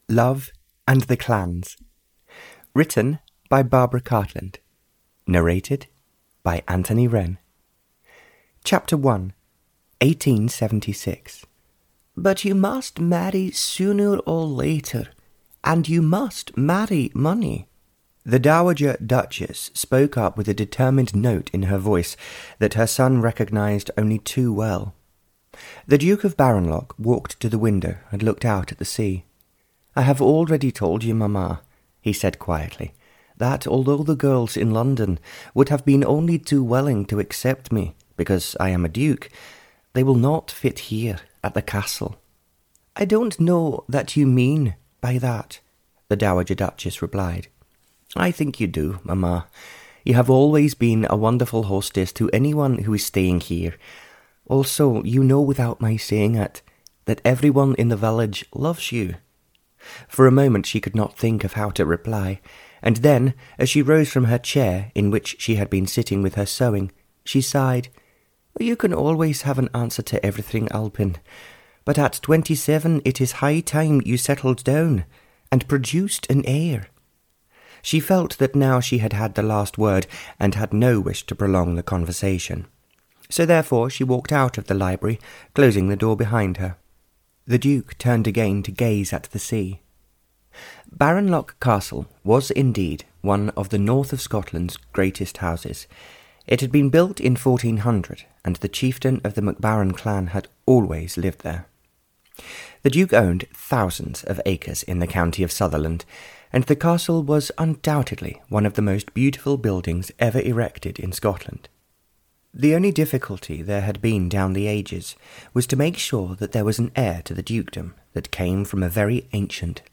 Audio knihaLove and the Clans (Barbara Cartland s Pink Collection 89) (EN)
Ukázka z knihy